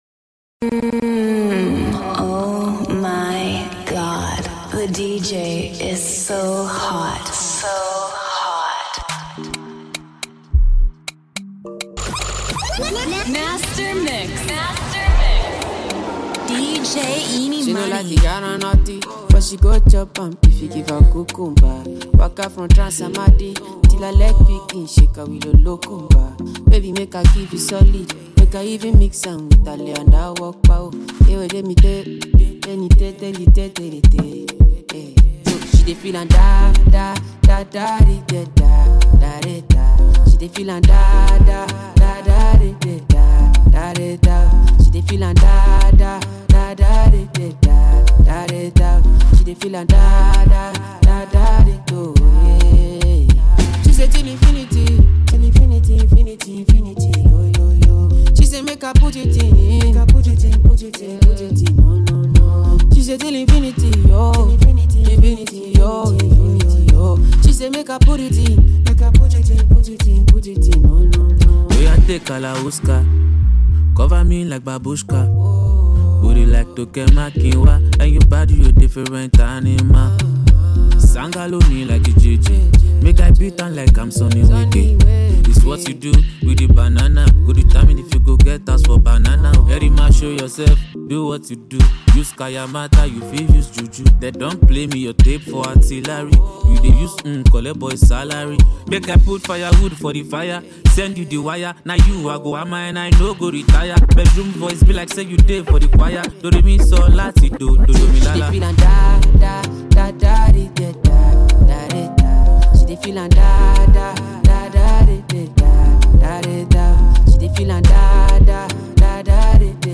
Number One Afrobeat Disc Jockey